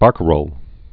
(bärkə-rōl)